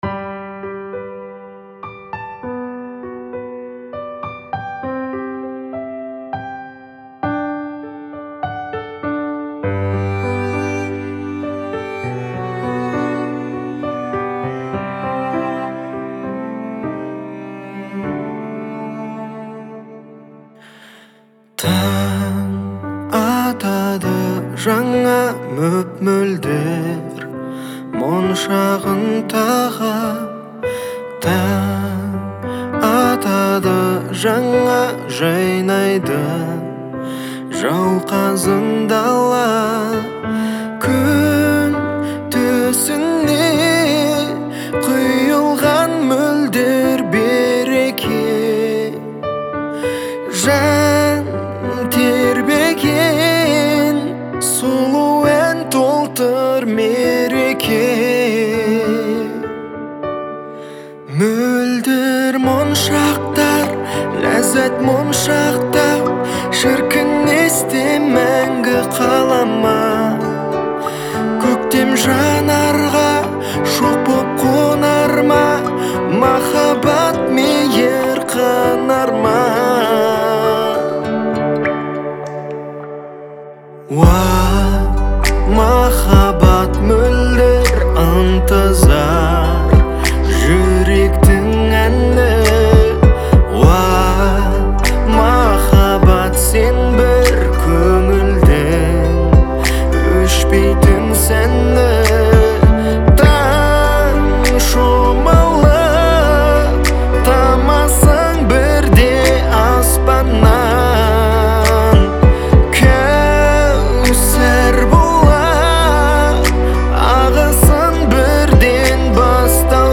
казахской певицы